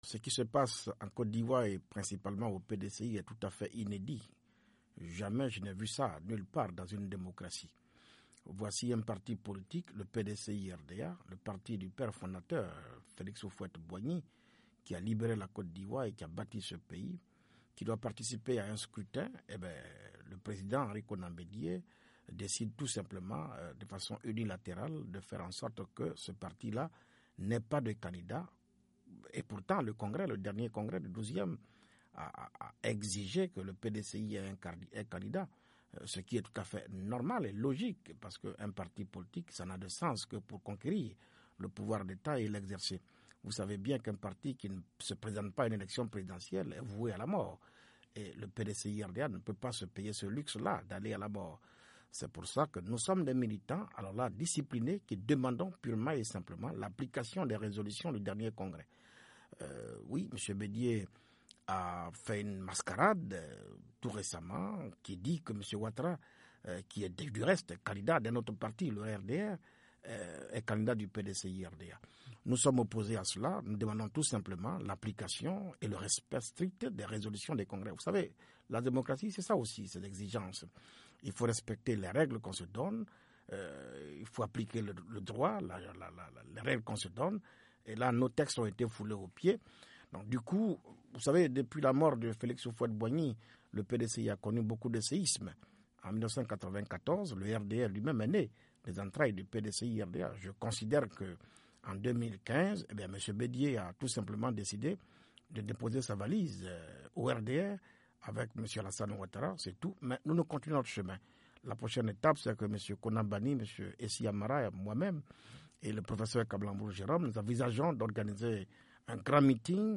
Dans une interview exclusive à VOA Afrique, Kouadio Konan Bertin, ou KKB, un des quatre frondeurs, affirme que le tsunami politique que connait le PDCI (Parti Démocratique de la Côte d’Ivoire) conduit déjà le parti vers l’éclatement.